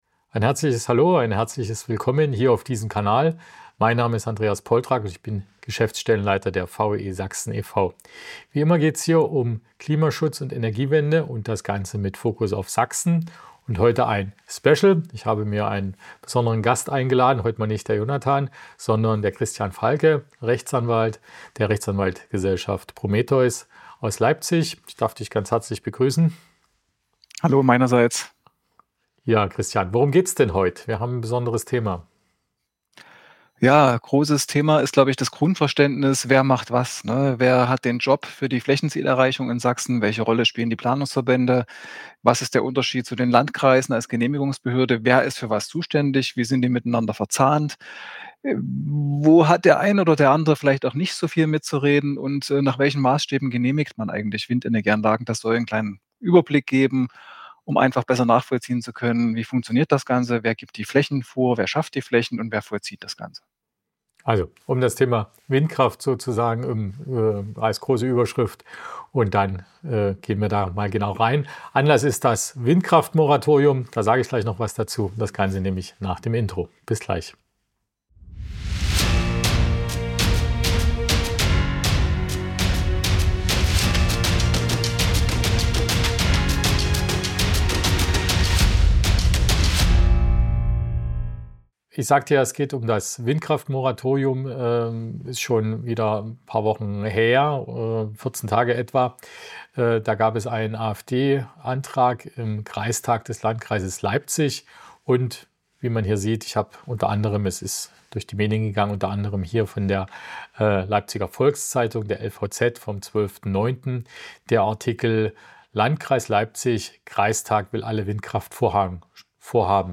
als Gast im virtuellen Studio